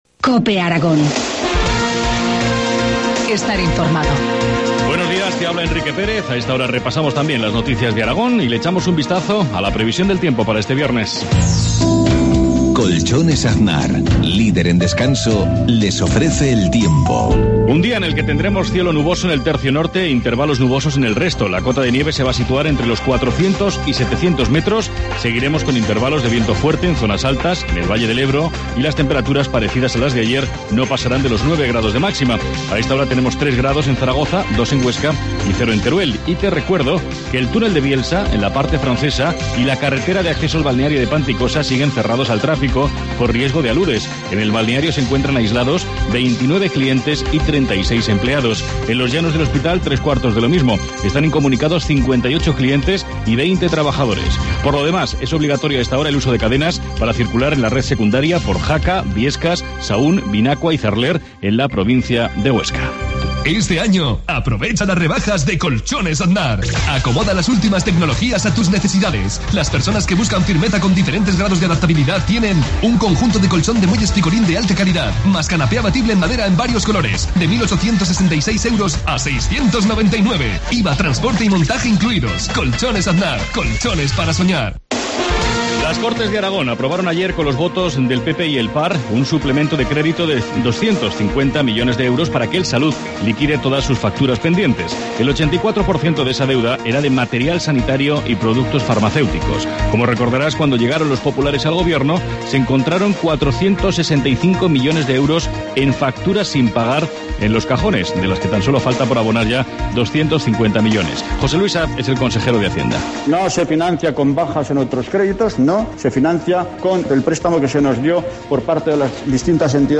Informativo matinal, viernes 8 de febrero, 7.53 horas